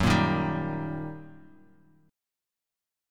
Eb/Gb chord
Eb-Major-Gb-2,1,1,0,x,x.m4a